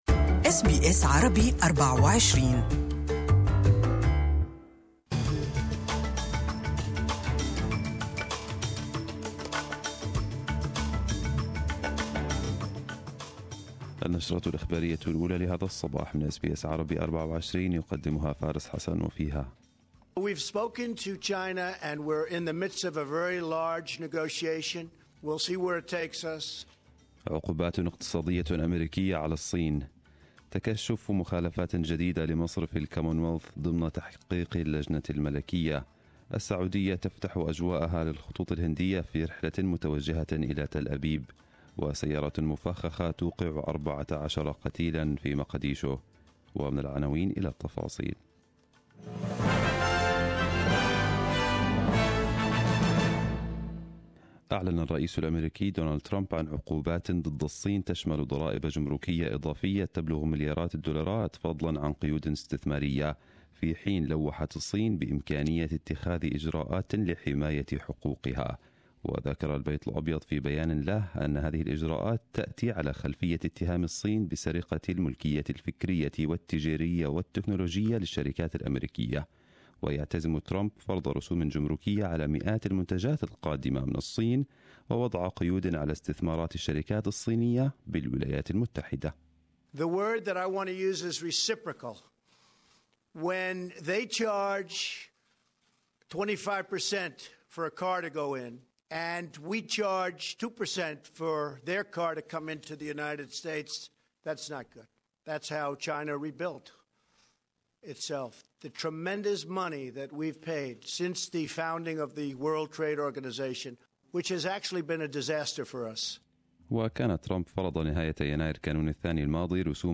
Arabic News Bulletin 23/03/2018